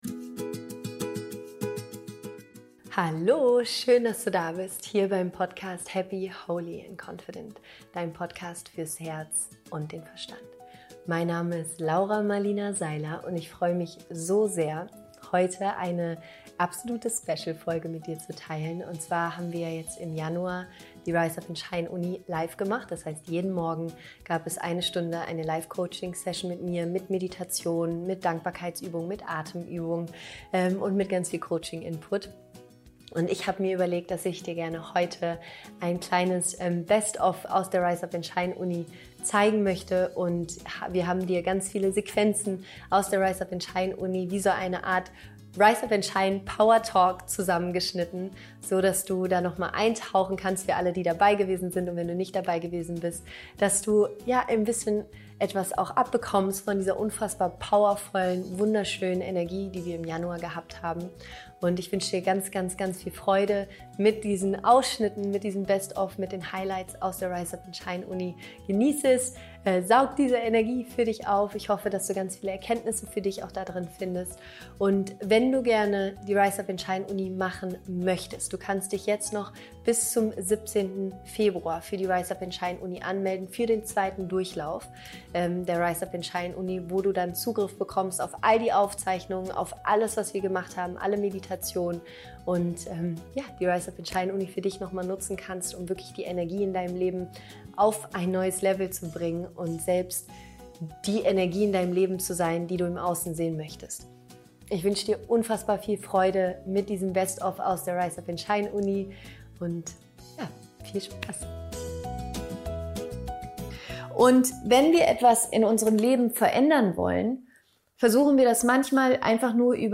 Im Januar hatten wir uns in der Rise Up & Shine Uni® jeden Morgen zur Live Session getroffen mit Meditationen, Atemübungen und ganz viel Coaching Input. Ich habe mir überlegt, dass ich gerne ein kleines Best Of aus der RUSU mit dir teilen möchte und wir haben eine Art RUSU PowerTalk zusammengeschnitten.
Ich wünsche dir ganz, ganz viel Freude mit diesen Ausschnitten und Highlights aus der RUSU 2020!